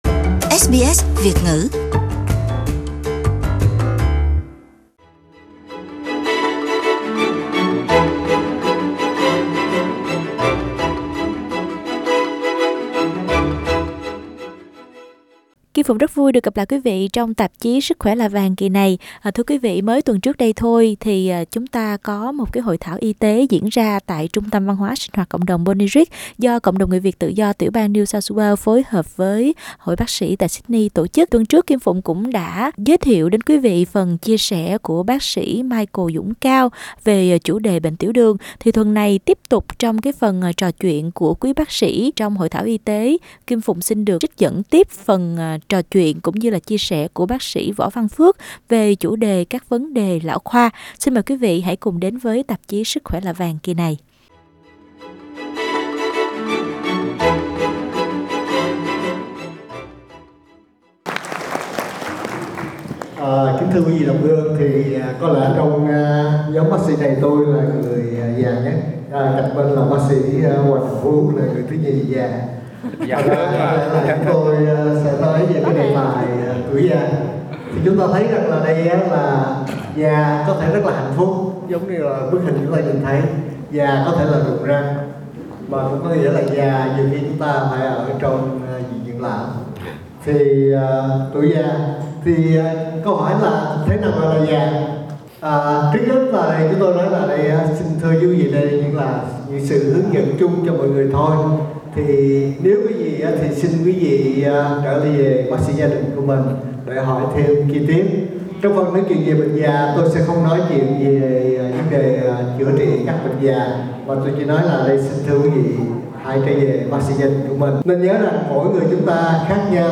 trong buổi nói chuyện ở Hội thảo y tế diễn ra vào ngày 2 tháng 6 vừa qua tại Trung tâm văn hóa sinh hoạt cộng đồng dành cho người Việt ở Sydney